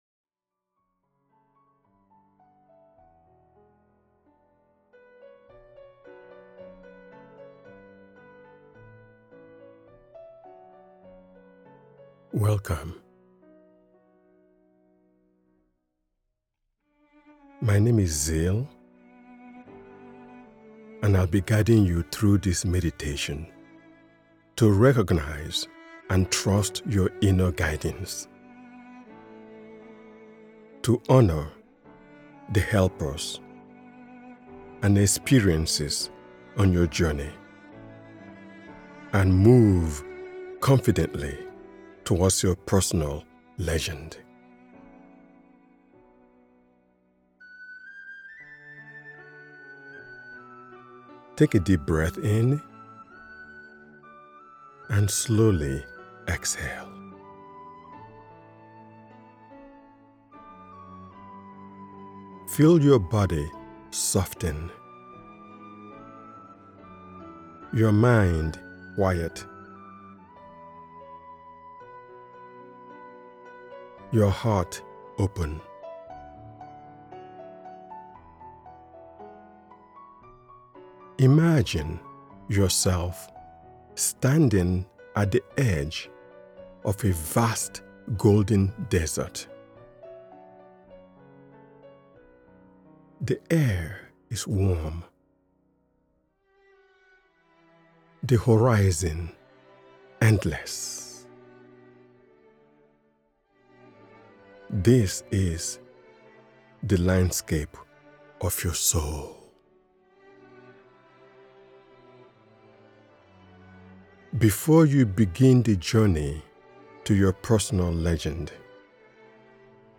The Alchemist: Discover Your Personal Legend is a transformative guided meditation designed to awaken your creative force and align you with a life of purpose, clarity, and inspired action.
Empowering affirmations are woven gently into the practice to reinforce clarity, confidence, and trust.